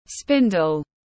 Con quay tiếng anh gọi là spindle, phiên âm tiếng anh đọc là /ˈspɪn.dəl/
Spindle /ˈspɪn.dəl/